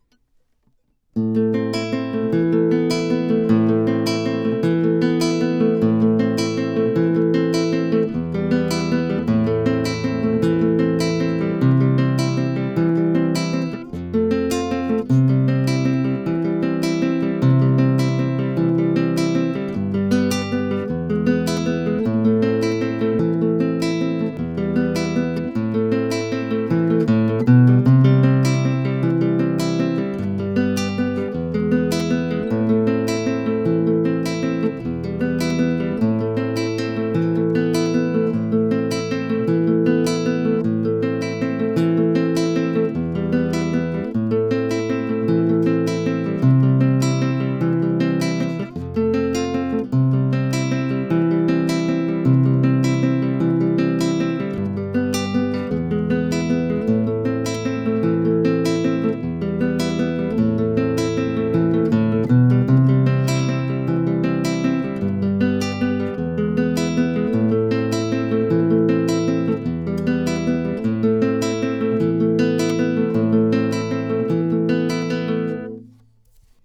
KytaraLive2.wav